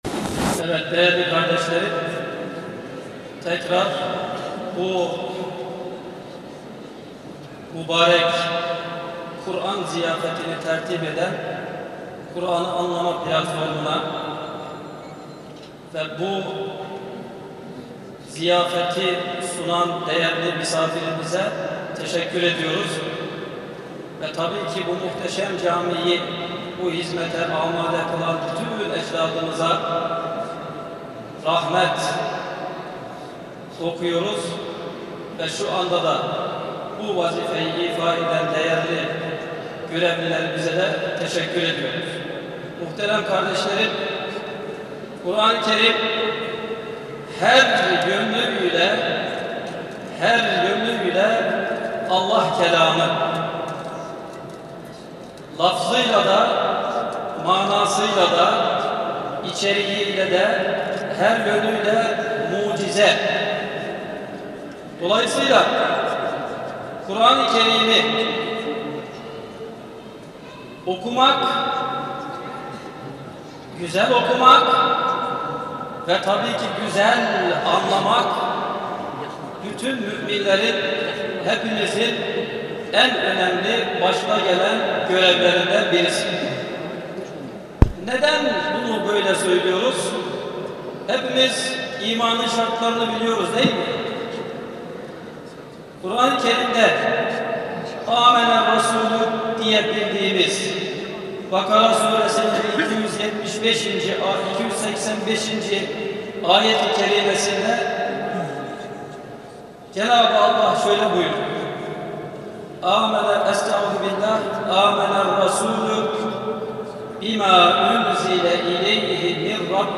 Vakıf Etkinlikleri